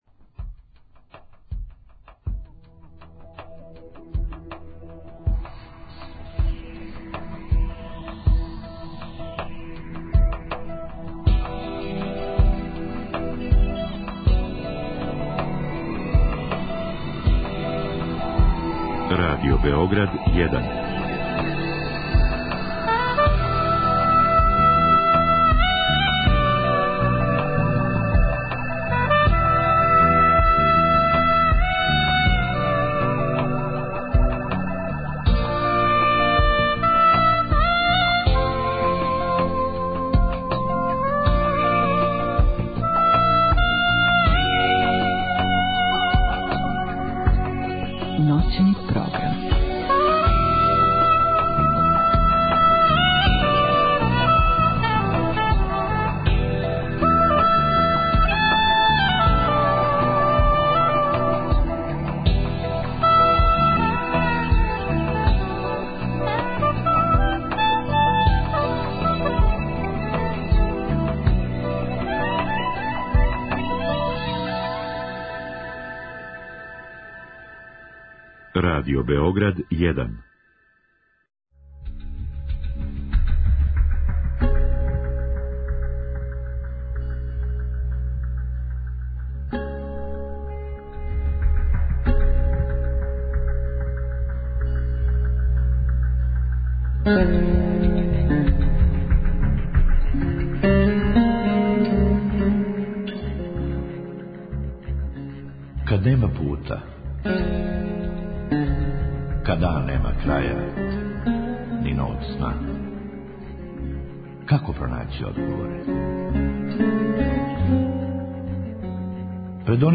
Нaши гoсти су психoтeрaпeути